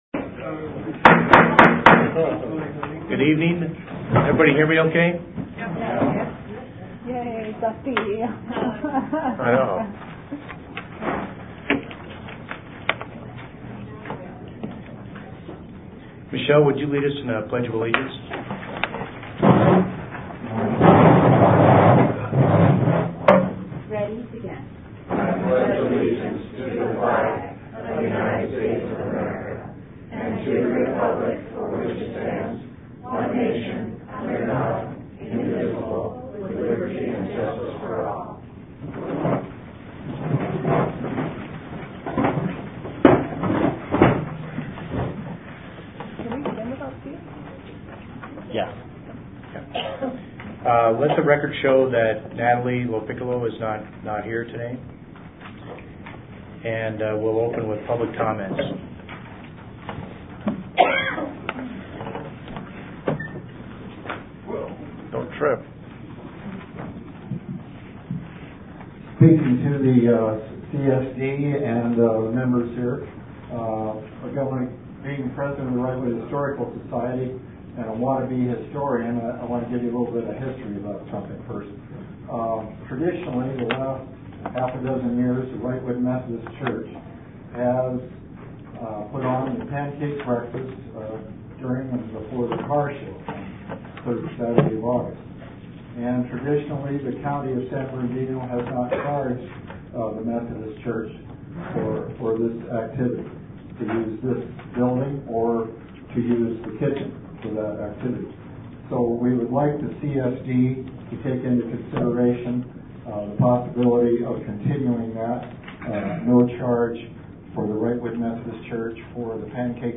WCSD Special Board Meeting - July 11, 2017